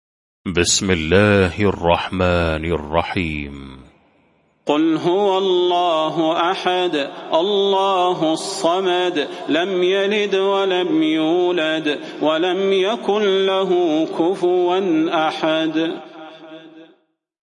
المكان: المسجد النبوي الشيخ: فضيلة الشيخ د. صلاح بن محمد البدير فضيلة الشيخ د. صلاح بن محمد البدير الإخلاص